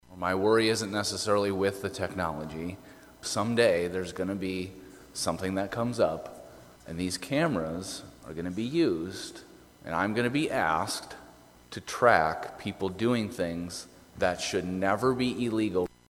REPRESENTATIVE ZACH DIEKEN OF GRANVILLE — A STATE TROOPER WHO’S NOT SEEKING RE-ELECTION — SAYS LEGISLATORS NEED TO WATCH WHAT HE CALLS “THE ENFORCEMENT WING OF THE EXECUTIVE BRANCH” LIKE A HAWK.